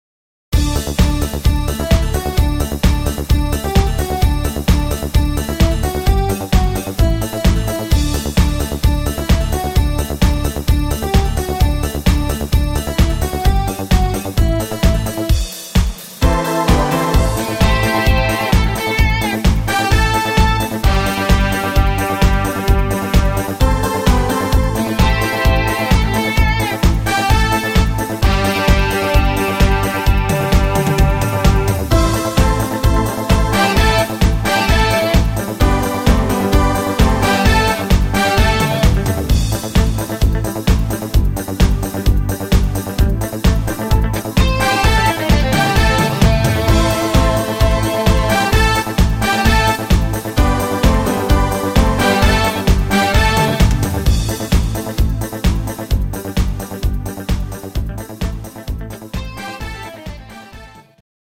Rhythmus  Discofox
Art  Deutsch, Hitmix, Kurzmedley, Schlager 90er